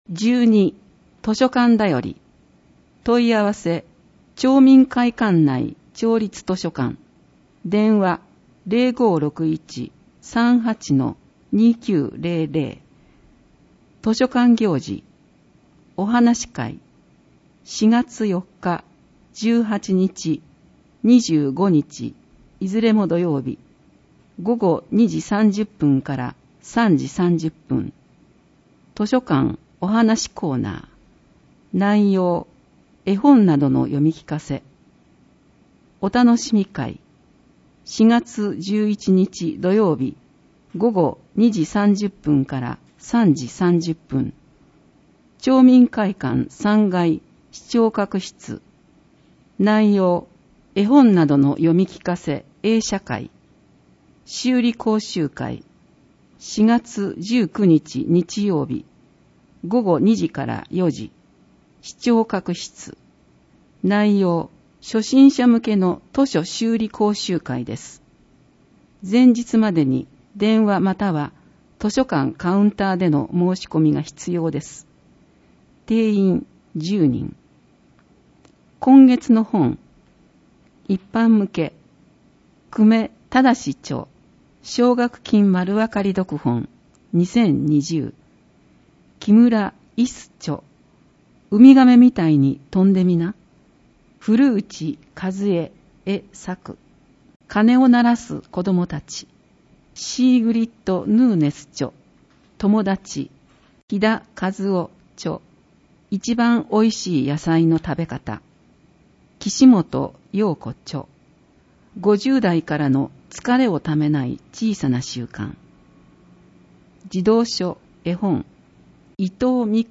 広報とうごう音訳版（2020年4月号）